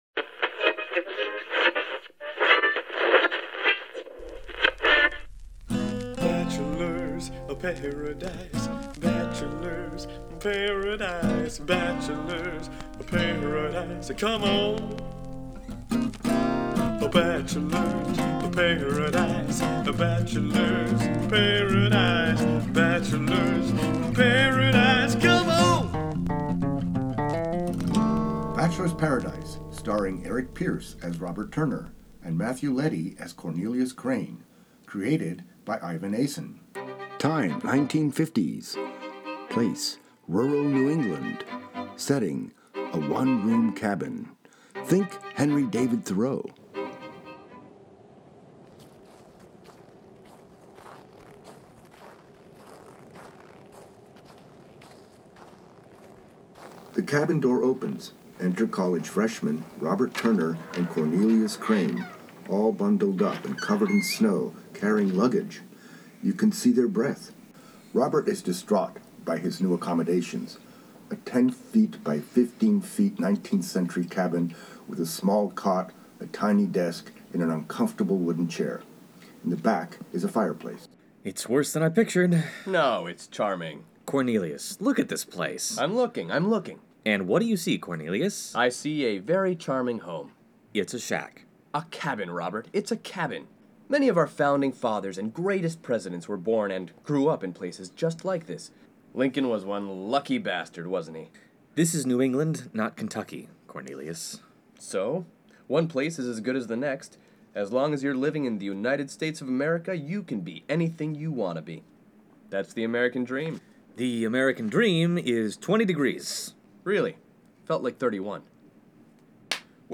Bachelor's Paradise is a radio play (AKA podcast) about the misadventures of college freshman Robert Turner and his inappropriate best friend, Cornelius Crane, XVI. It's the 1950s.